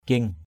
/kie̞ŋ/ aiek: ‘akiéng’ a_k`$